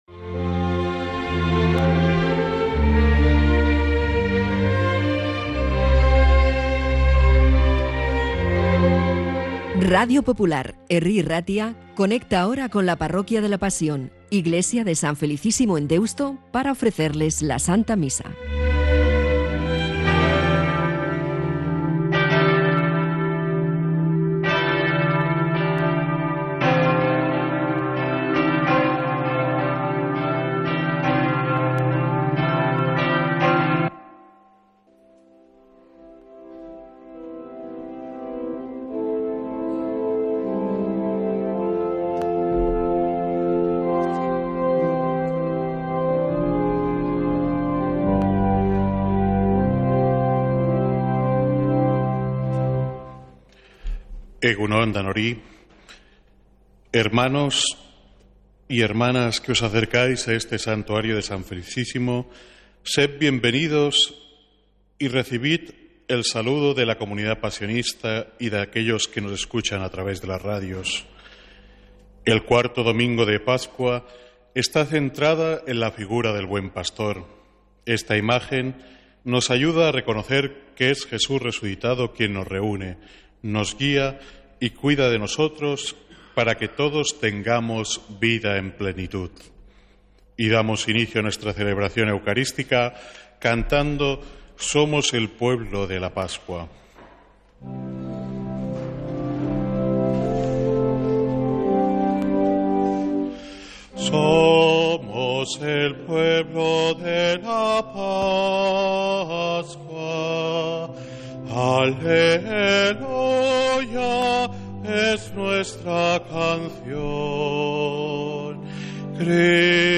Santa Misa desde San Felicísimo en Deusto, domingo 11 de mayo